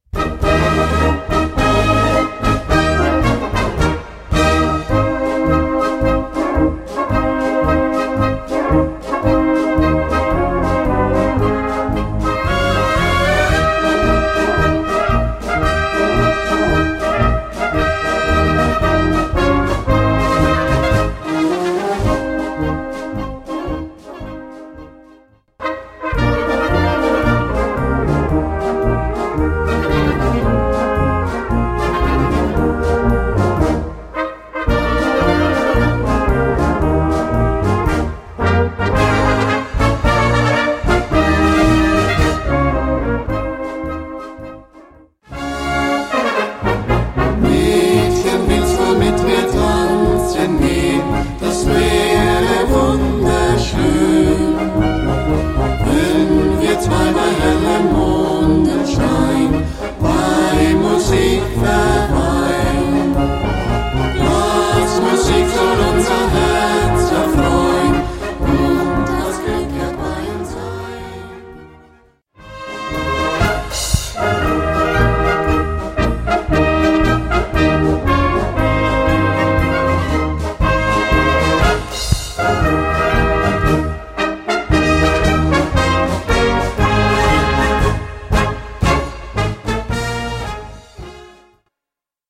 Eine böhmische Polka mit Gesangsstimme im Trio.